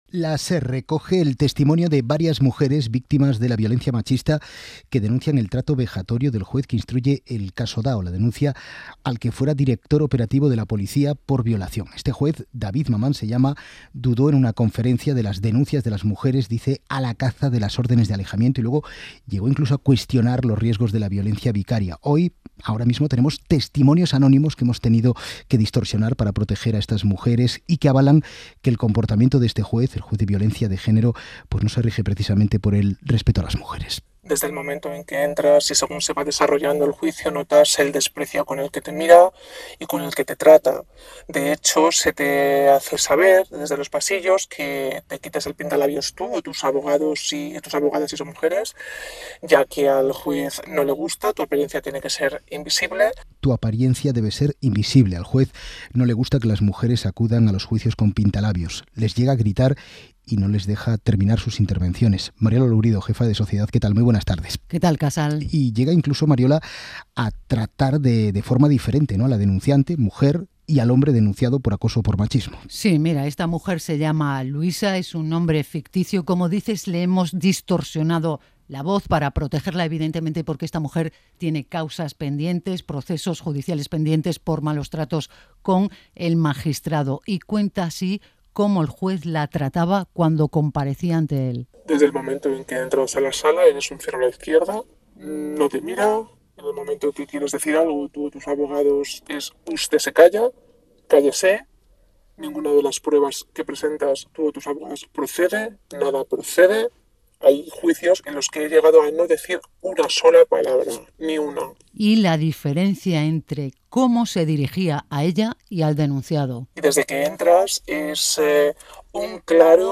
'Hora 14' es el informativo líder del mediodía.